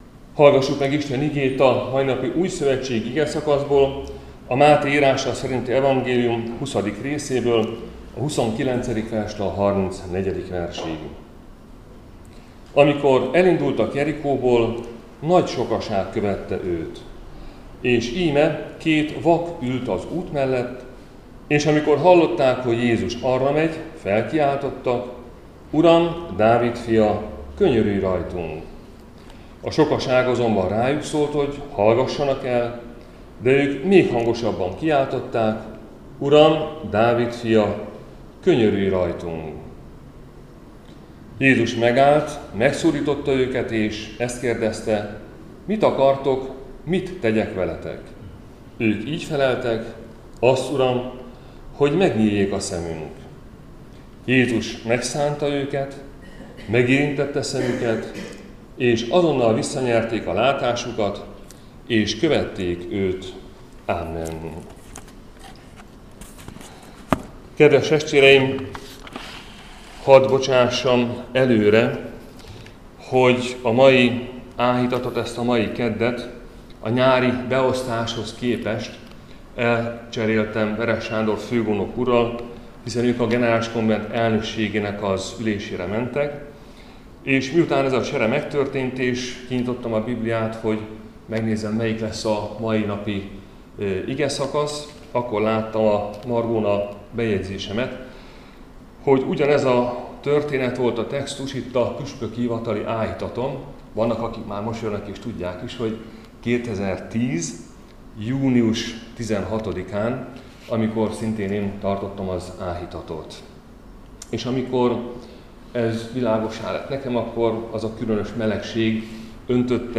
Áhítat, 2024. szeptember 10.